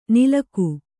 ♪ nilaku